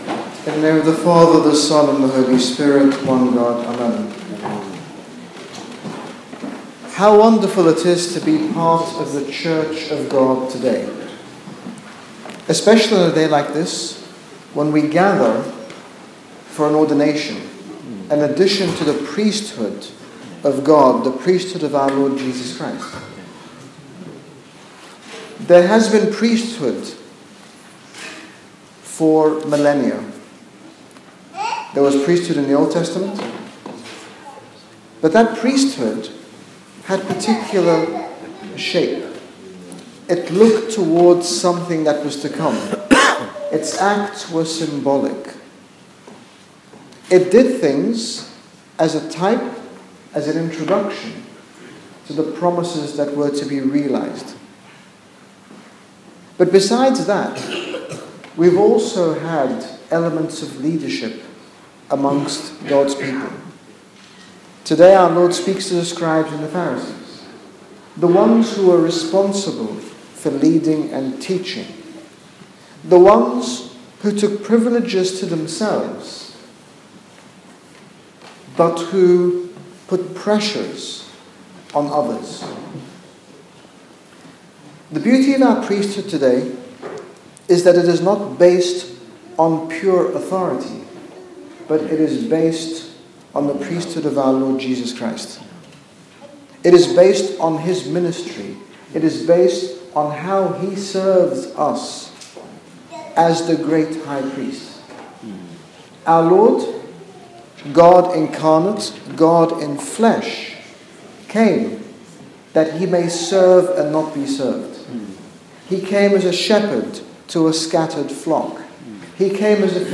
In this short sermon His Grace Bishop Angaelos talks about priesthood, the Sacraments and the importance of cleansing our hearts to allow our Lord Jesus Christ to enter in.